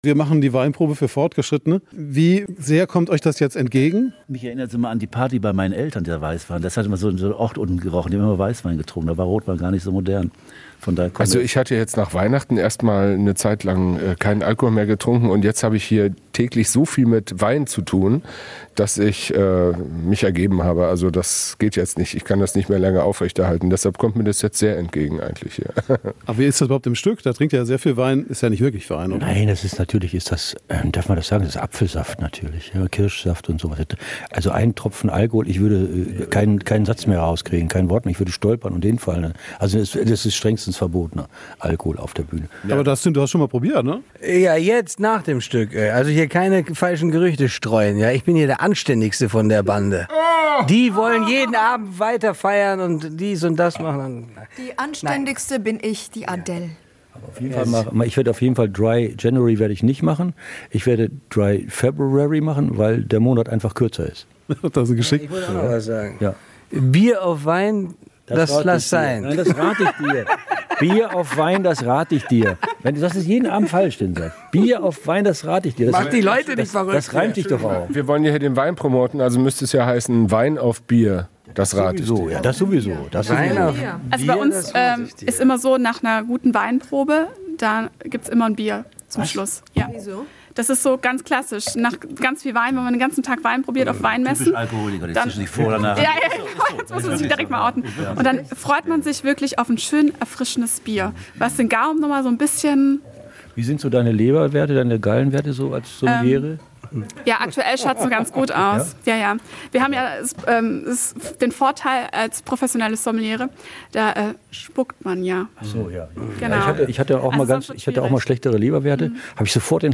In der Kulisse des Stücks ‚Weinprobe für Anfänger‘ machen es sich die fünf Schauspieler bequem.
Schnell entsteht ein angeregtes Gespräch, denn der Wein verbindet die Schauspieler und die Expertin.